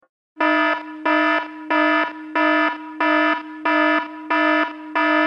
警報音
警報音効果音.mp3